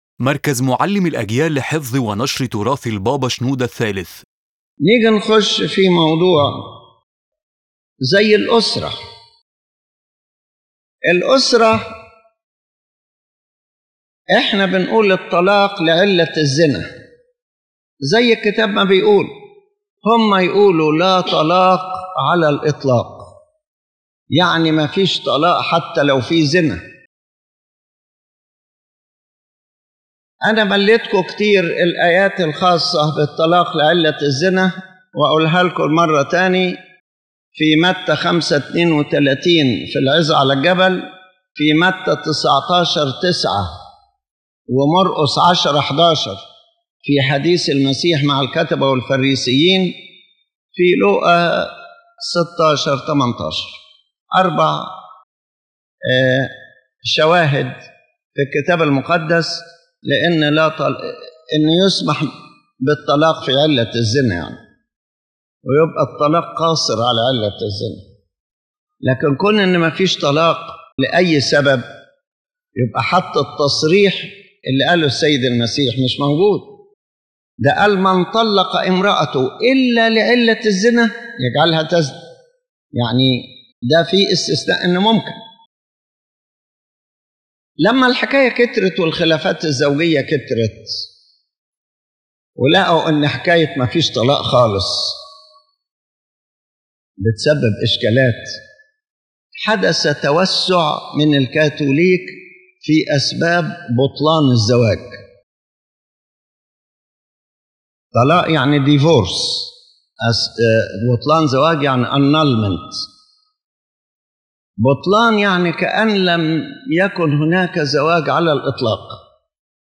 This lecture discusses one of the doctrinal and educational disagreements between the Coptic Orthodox Church and the Catholic Church, specifically regarding marriage and divorce within the Christian family, explaining the biblical and spiritual foundations of each position.